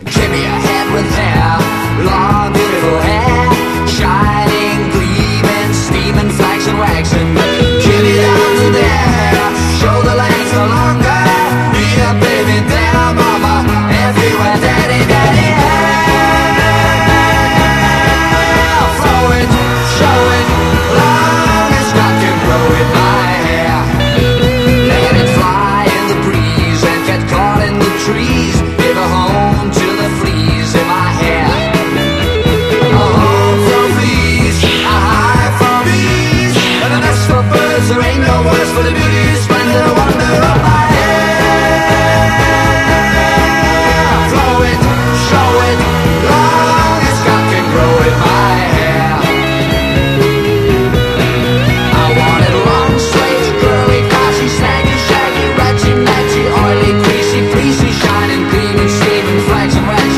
ROCK / 60'S / PSYCHEDELIC ROCK
69年シアトル・ガレージ・サイケ・レア45！
重厚なギターリフとダイナミックなリズムで構成されたトラックで、独自のサイケデリックな世界に引き込みます。